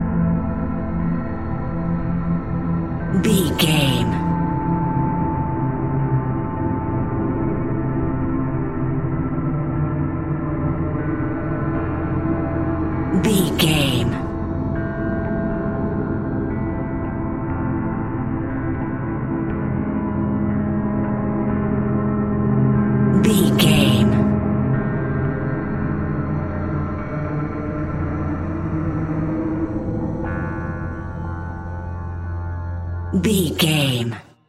Ionian/Major
F♯
industrial
dark ambient
EBM
synths